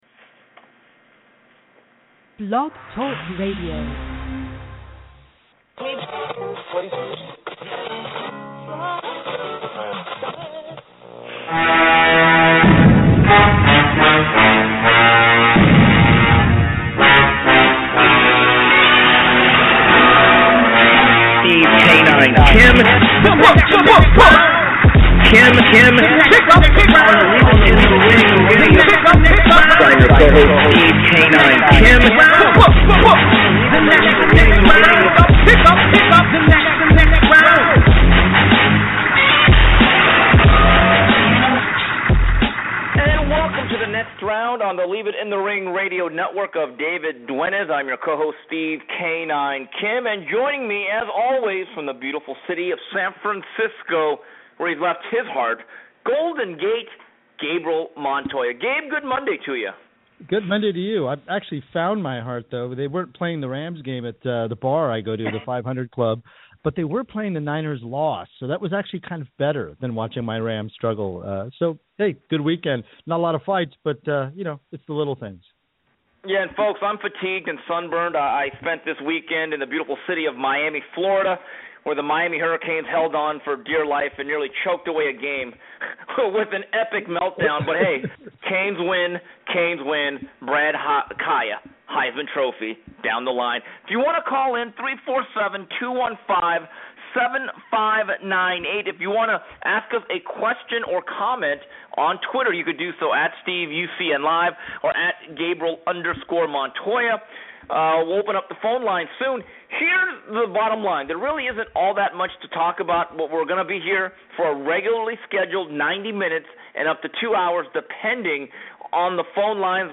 And as always, news, notes and your calls.